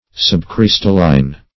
Subcrystalline \Sub*crys"tal*line\, a. Imperfectly crystallized.